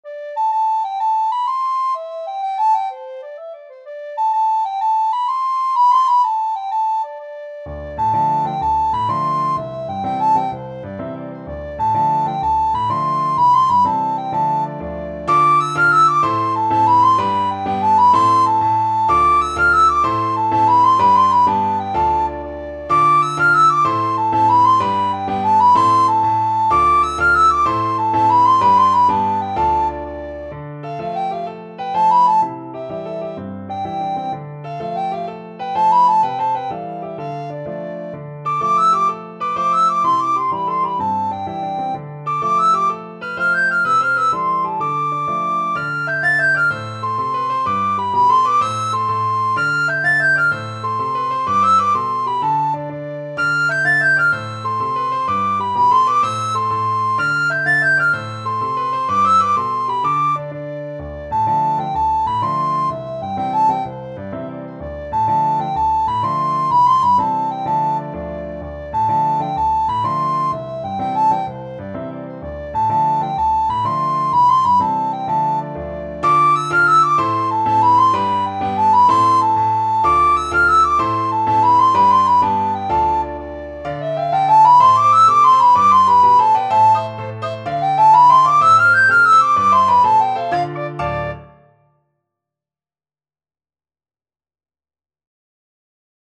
Classical Trad. The Road To Lisdoonvarna Soprano (Descant) Recorder version
12/8 (View more 12/8 Music)
A minor (Sounding Pitch) (View more A minor Music for Recorder )
Fast .=c.126
Irish